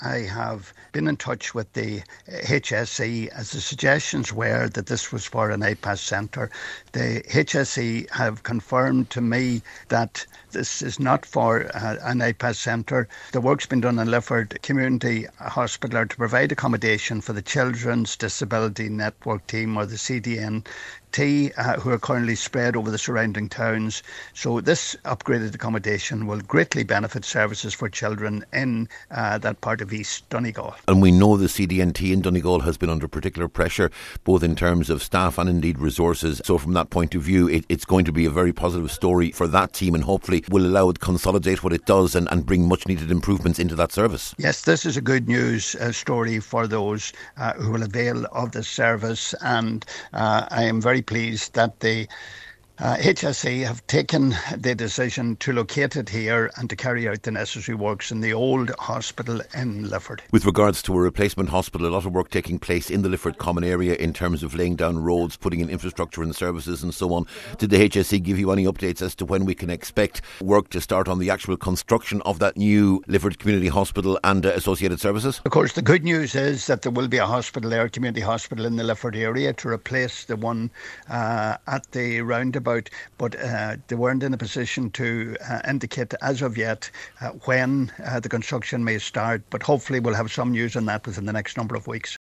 Deputy Pat The Cope Gallagher told Highland Radio News today that he made contact with the HSE on foot of concerns within sections of the local community that the old hospital building was to be used to house asylum seekers.